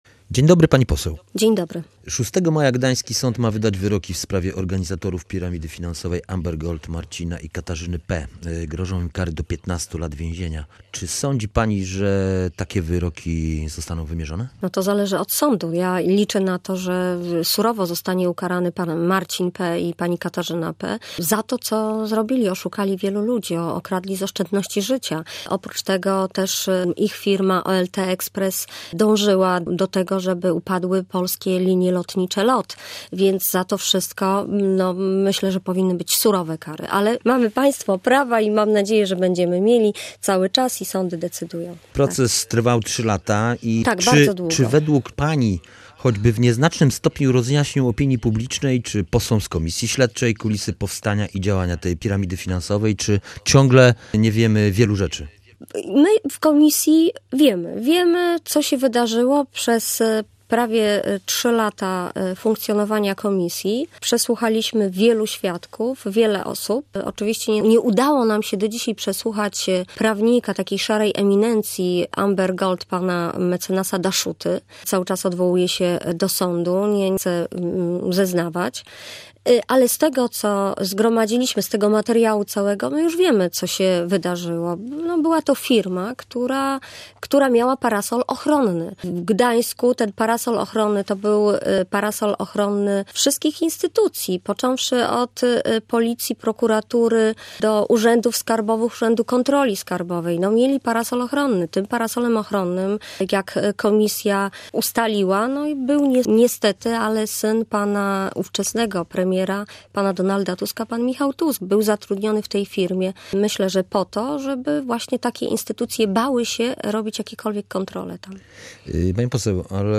O to zapytaliśmy członkinię sejmowej Komisji Śledczej ds. Amber Gold – Iwonę Arent.